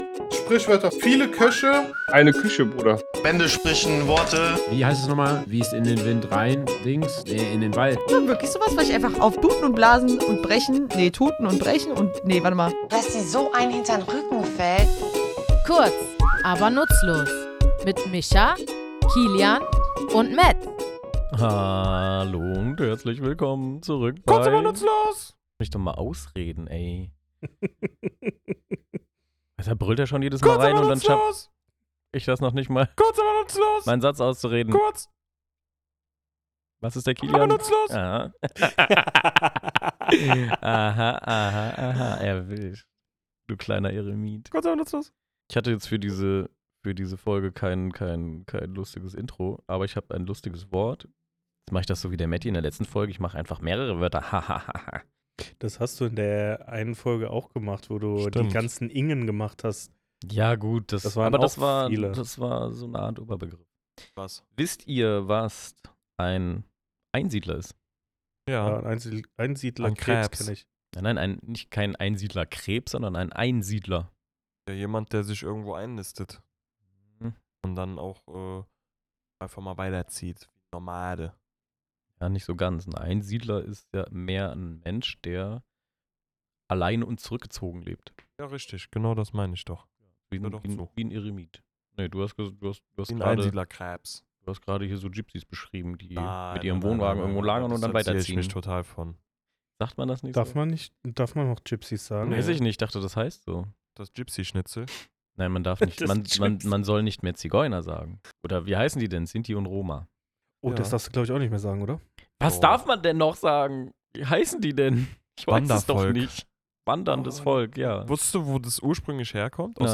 Wir, drei tätowierende Sprachliebhaber, graben in unserem Tattoostudio nach der Herkunft und Geschichte dieser Redensart und schauen, was unser Besitz mit Sprache zu tun hat.